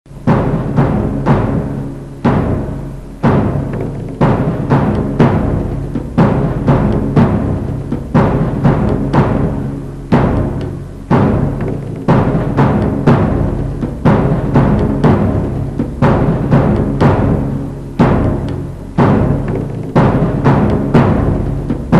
Боевой барабан, мелодия перед атакой